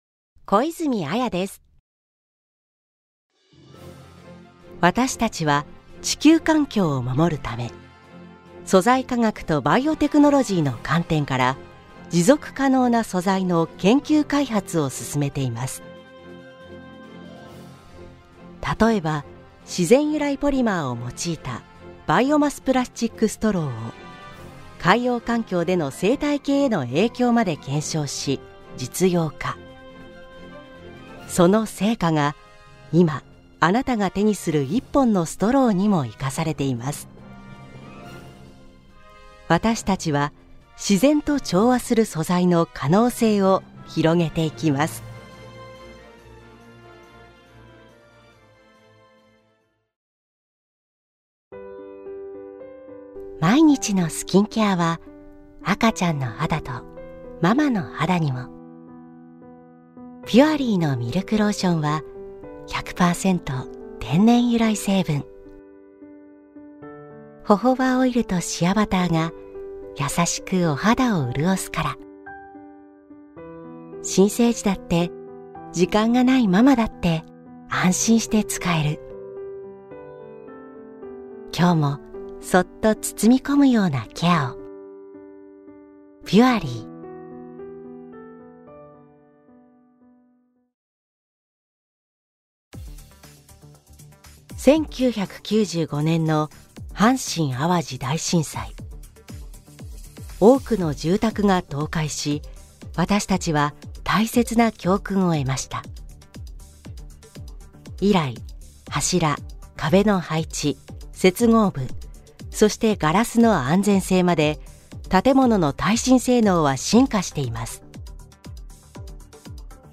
• 透明感ある正統派
• 音域：高～中音
• 声の特徴：：さわやか、落ち着き、正統派